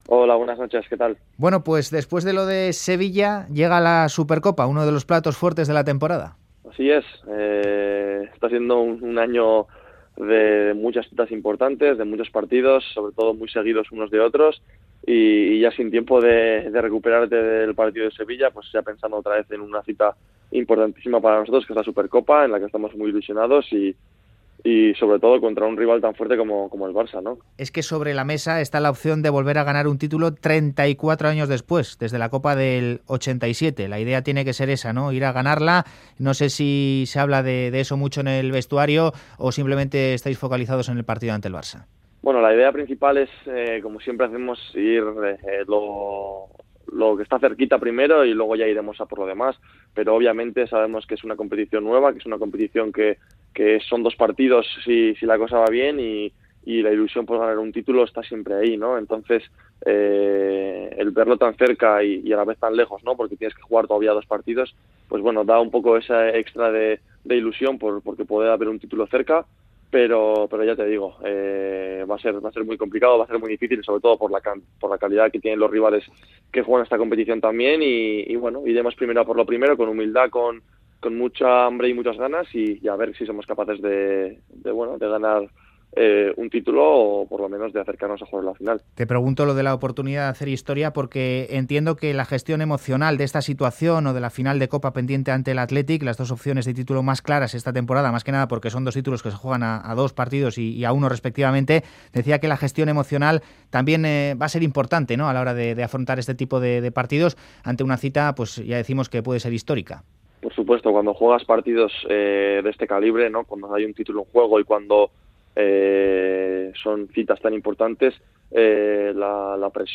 Audio: El centrocampista navarro atiende la llamada de "Fuera de Juego" en la previa de enfrentarse al Barcelona en la semifinal de la Supercopa